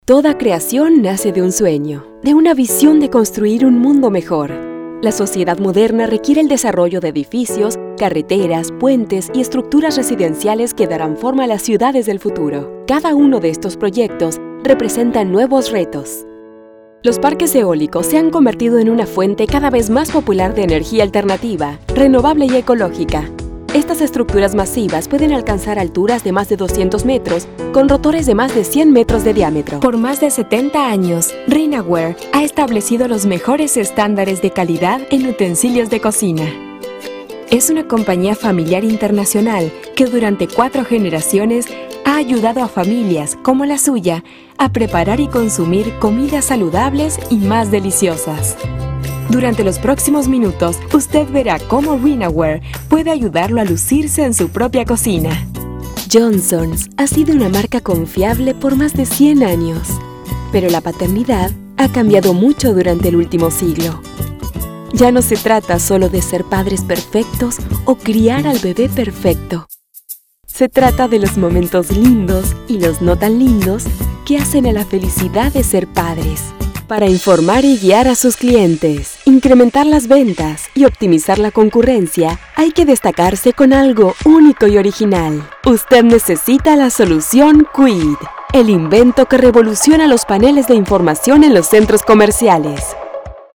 Female Voice Over, Dan Wachs Talent Agency.
Sincere, Conversational, Warm, Inspiring
Corporate